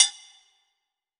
WAGOGO LW.wav